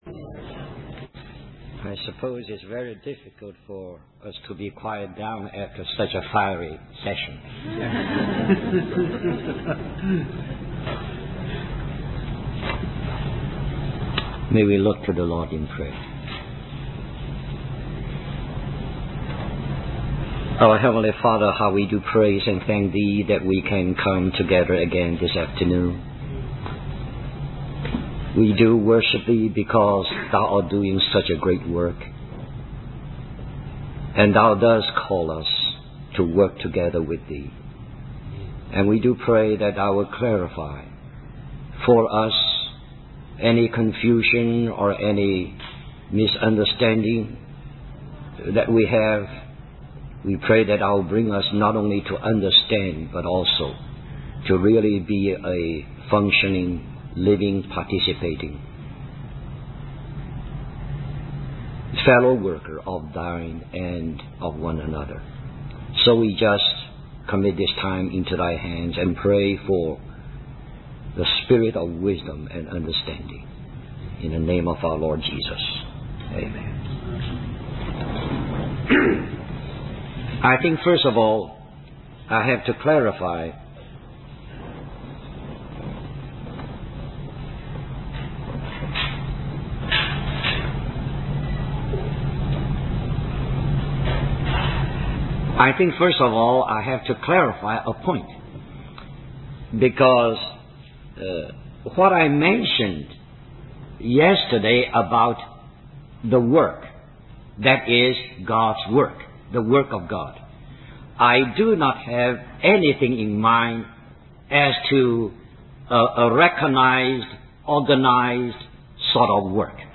Responsibility in the Work - Questions and Answers